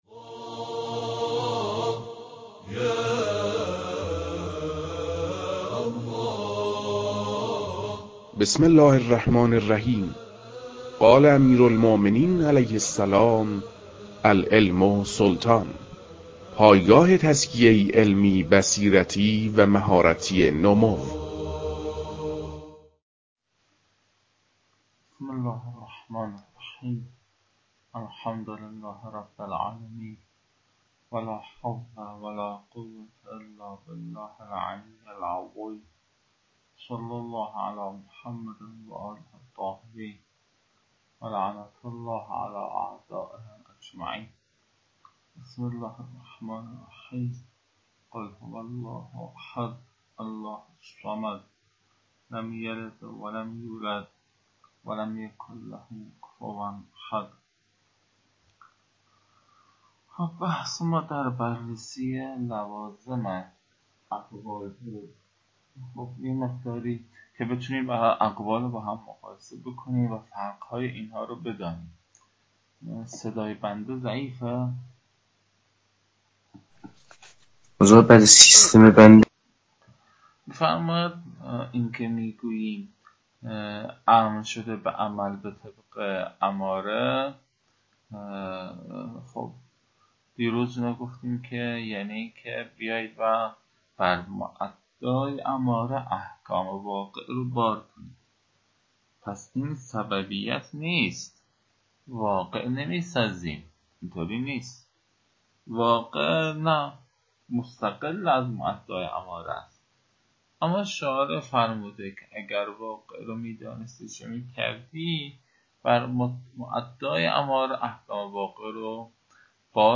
فایل های مربوط به تدریس مبحث رسالة في القطع از كتاب فرائد الاصول